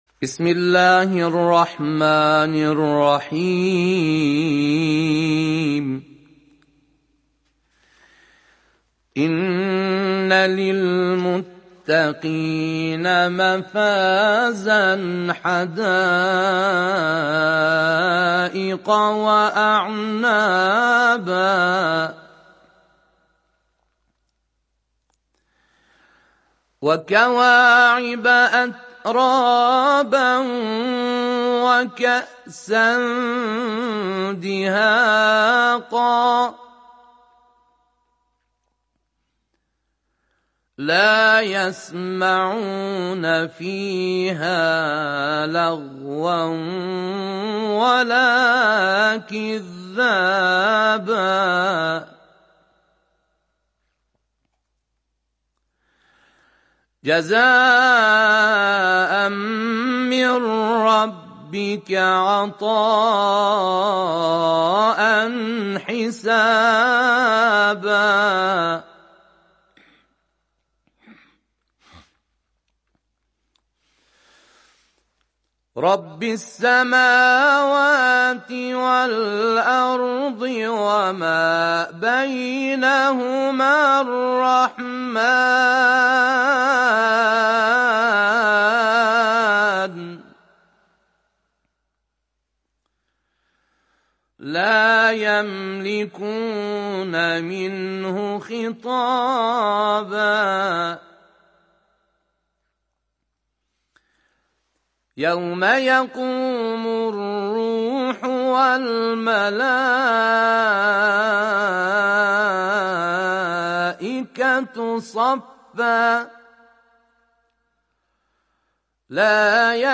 در ادامه تلاوتی از این قاری ممتاز قرآن کریم از آیات پایانی سوره نبأ تقدیم مخاطبان ایکنا می‌شود: